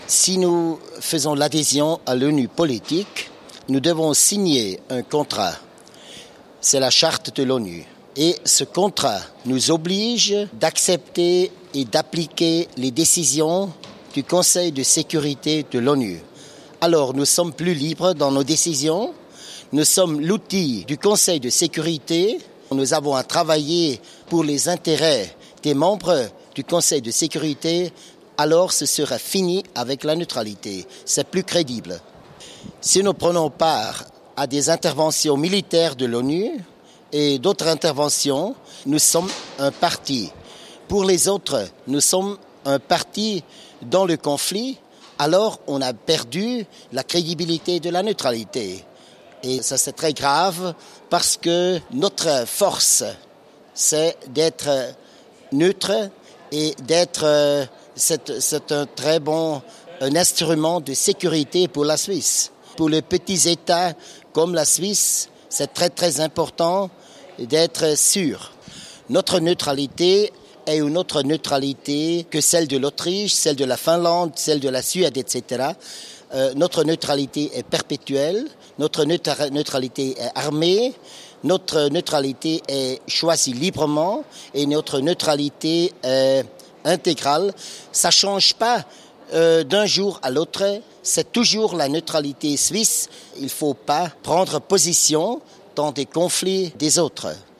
Interview de Hans Fehr sur l adhésion de la Suisse à l ONU